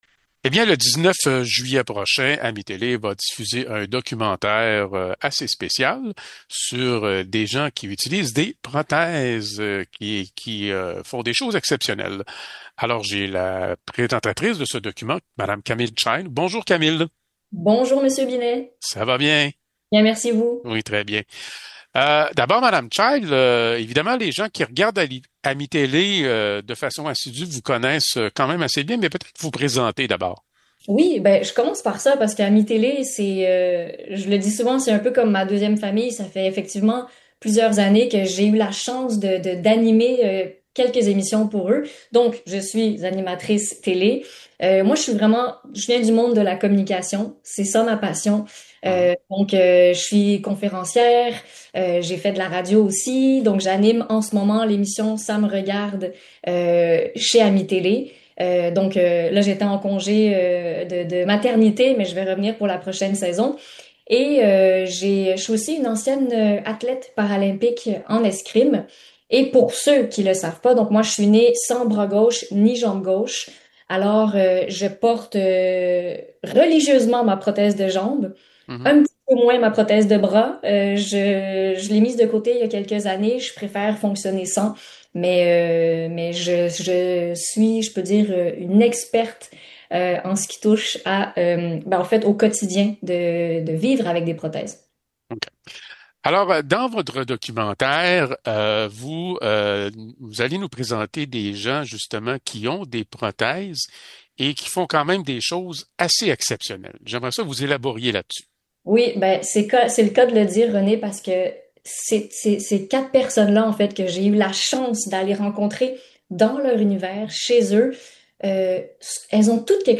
Nous continuons nos entrevues avec les artisans d’AMI-Télé.